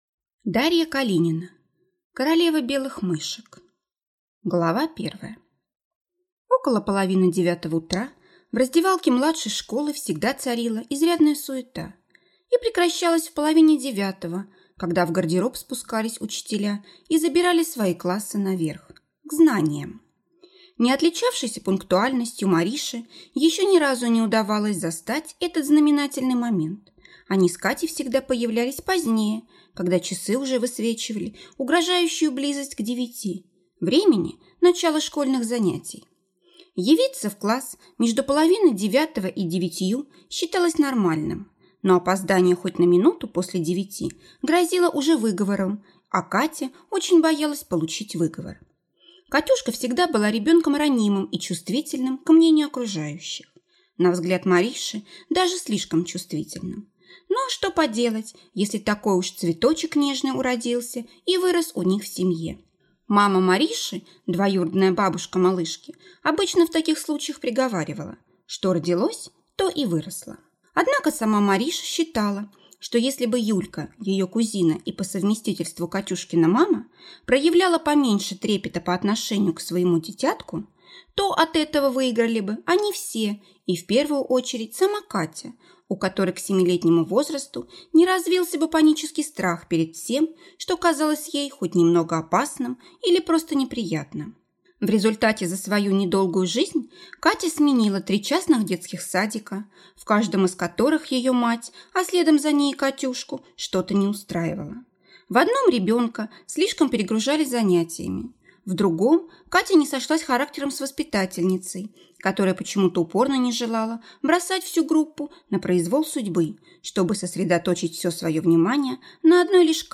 Аудиокнига Королева белых мышек | Библиотека аудиокниг